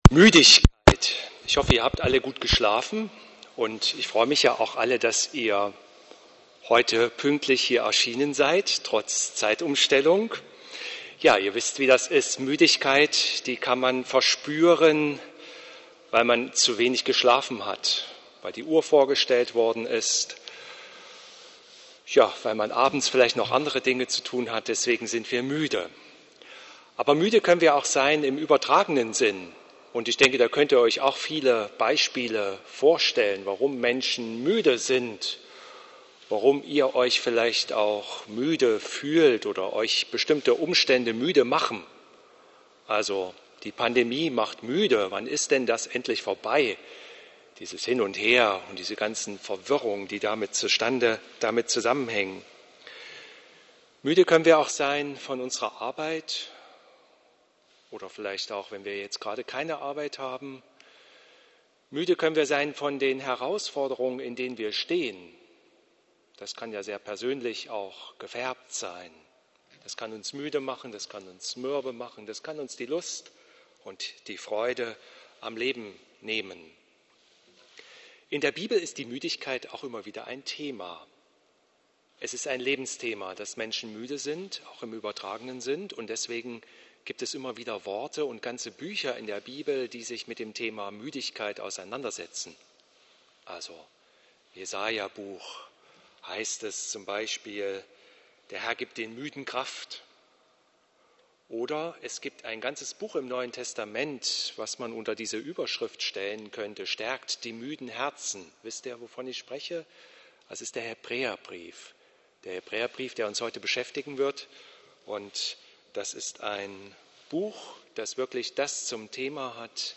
Hören Sie hier die Predigt zu Hebräer 12,1-3